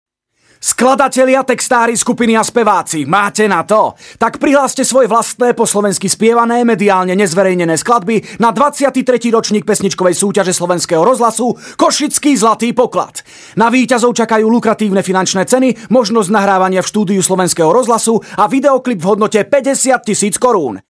Sprecher slowakisch für TV / Rundfunk / Industrie.
Kein Dialekt
Sprechprobe: Industrie (Muttersprache):
Professionell slovakian voice over artist